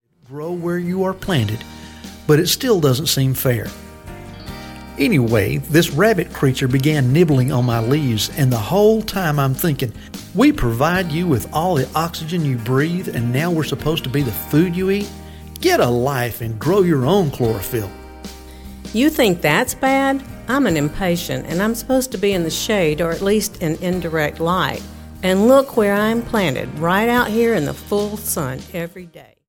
04 Plants Point Of View – A Recitation